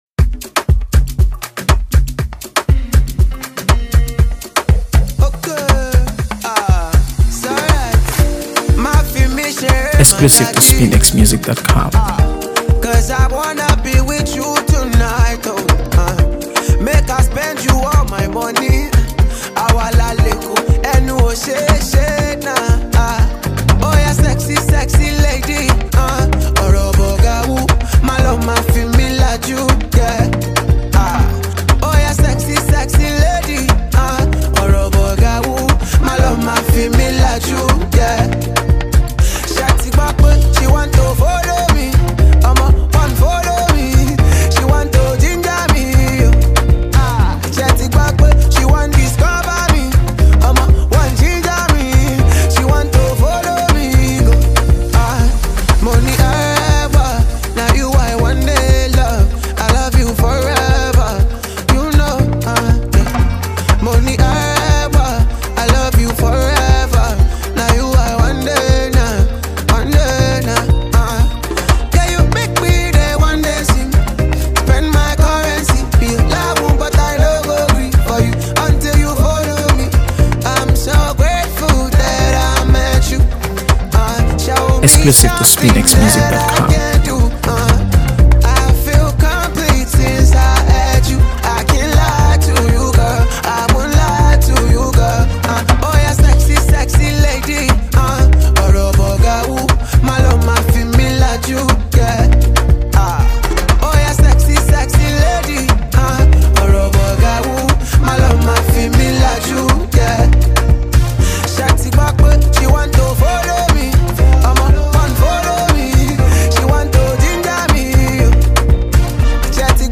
AfroBeats | AfroBeats songs
smooth, sultry new track
Built on catchy melodies and feel-good Afrobeats grooves